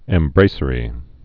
(ĕm-brāsə-rē)